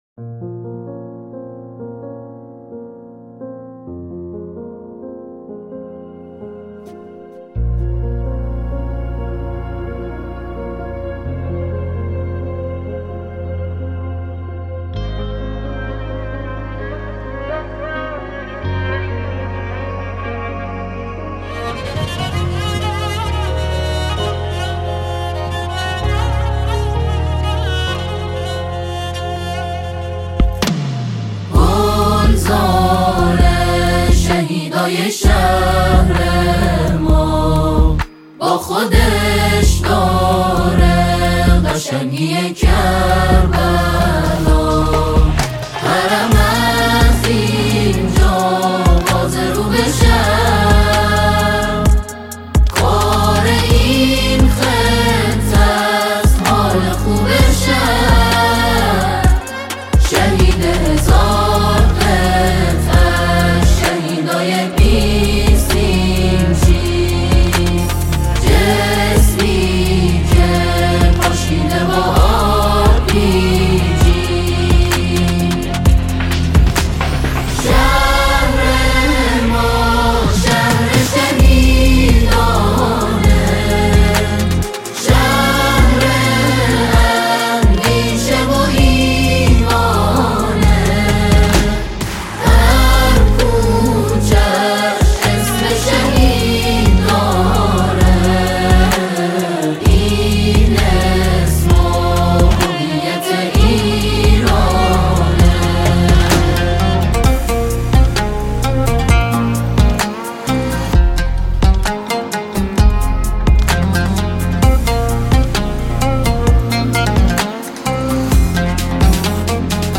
با شکوه و صلابت
ژانر: سرود